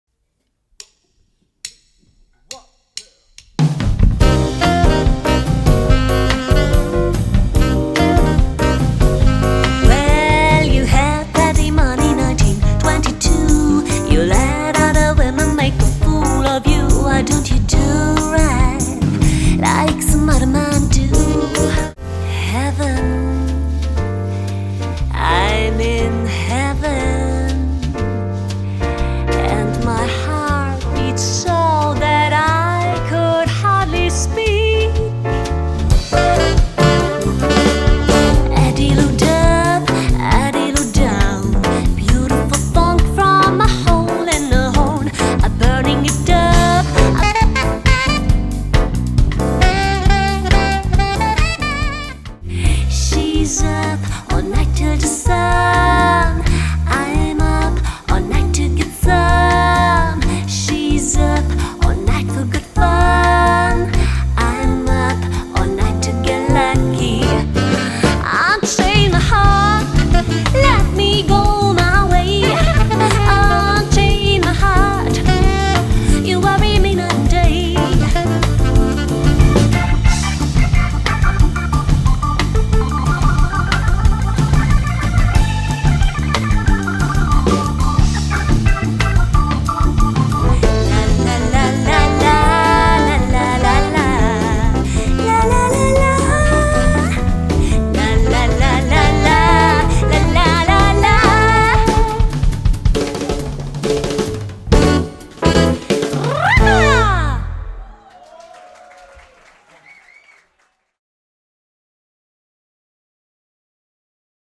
Latin, Dinnerjazz
1_Jazzband-aus-Wien-Lounge-_-Latin-_-Dinnerjazz-_-Gala-_-Firmenfeier-_-Loungemusik.mp3